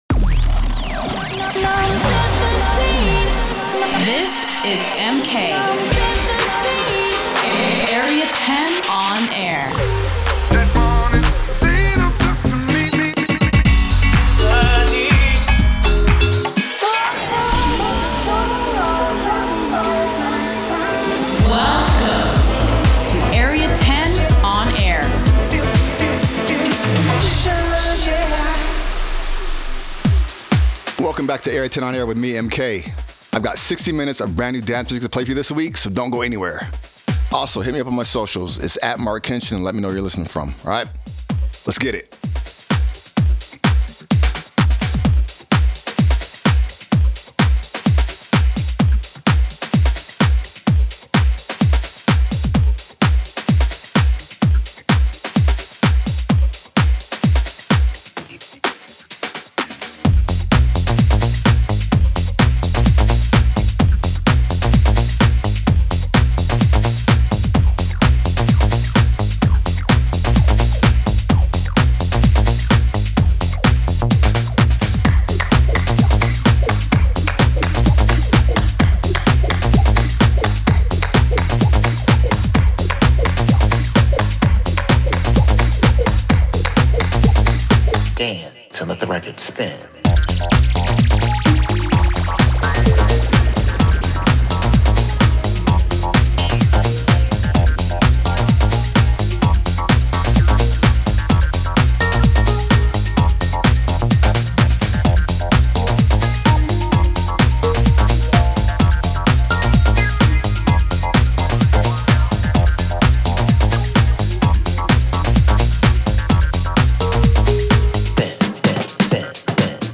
Delving in to house and techno, new and old